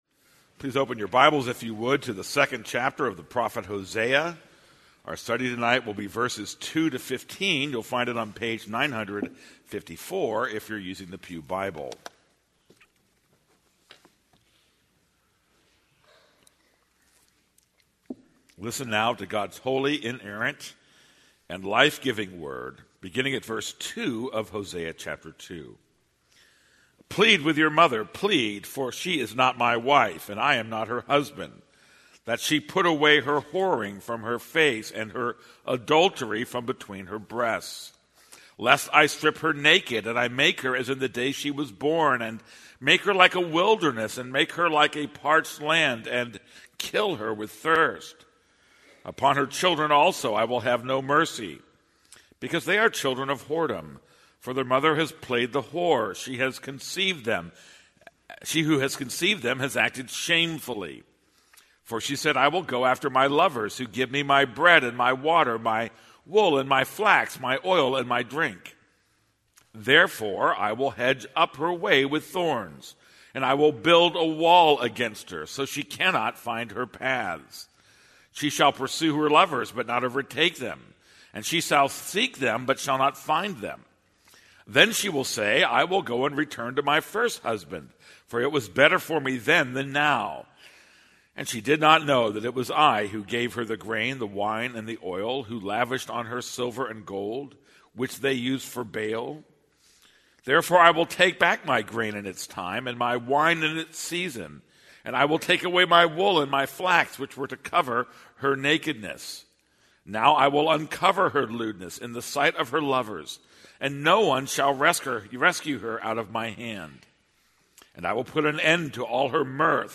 This is a sermon on Hosea 2:2-15.